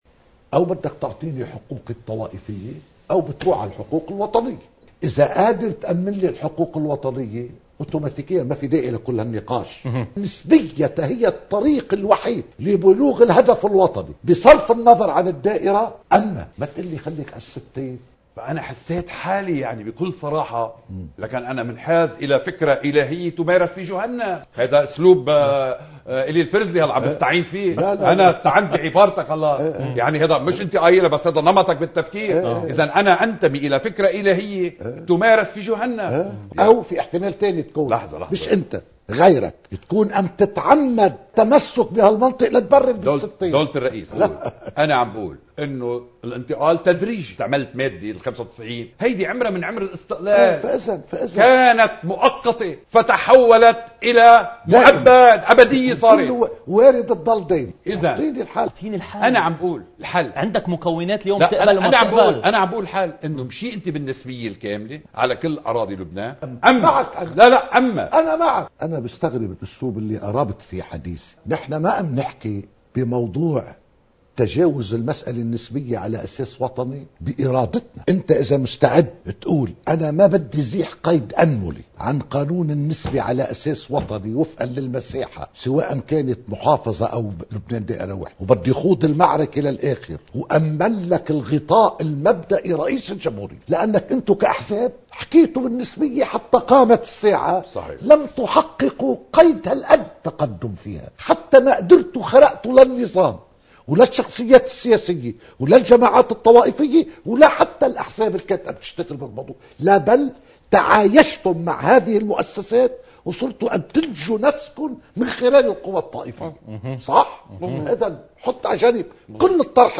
مقتطف من حديث / حوار نائب رئيس الأسبق للمجلس النيابي ايلي الفرزلي على قناة الـ”nbn”، ضمن برنامج “آخر كلام”